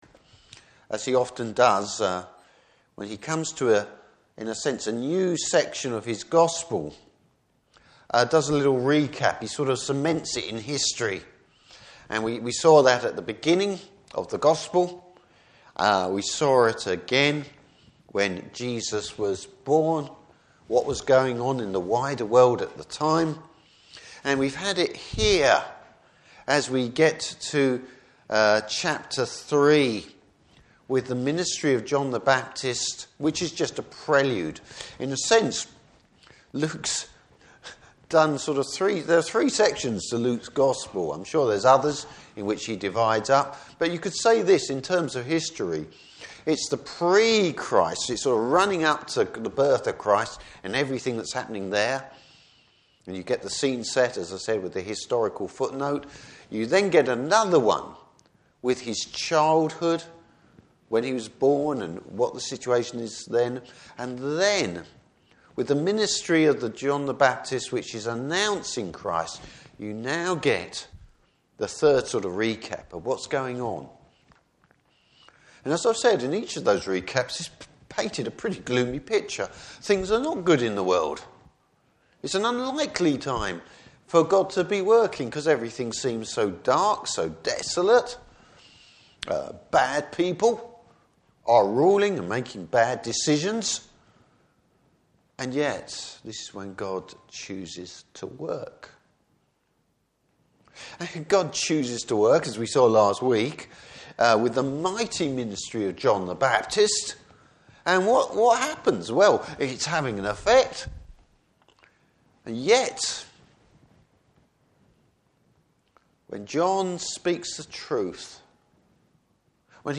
Service Type: Morning Service Bible Text: Luke 3:21-38.